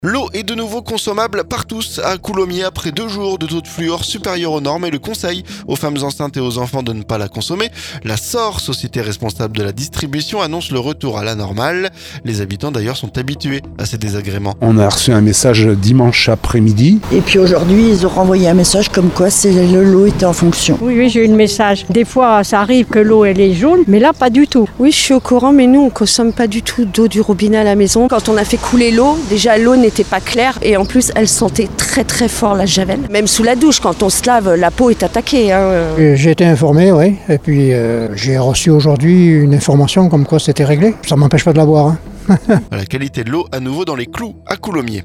COULOMMIERS - Les habitants réagissent à la détérioration temporaire de la qualité de l'eau
La Saur, société responsable de la distribution, annonce le retour à la normale. Micro tendu aux habitants.